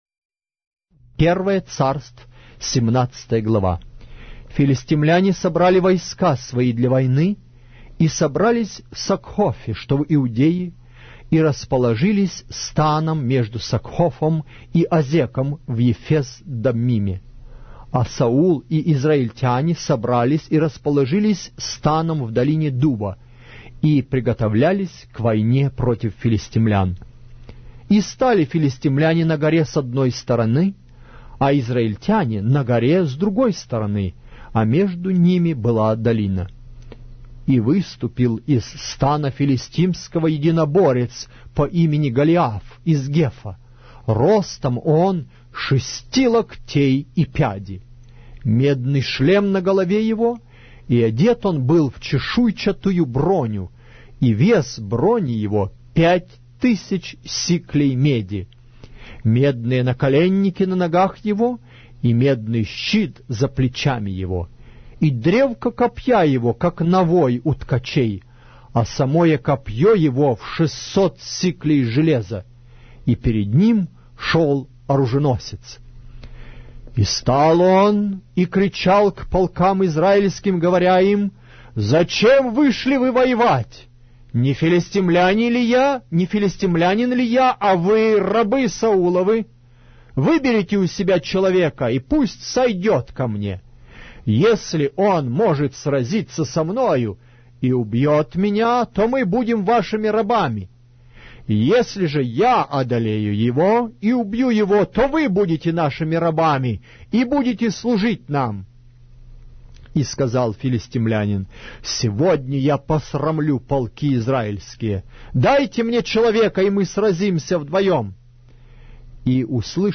Глава русской Библии с аудио повествования - 1 Samuel, chapter 17 of the Holy Bible in Russian language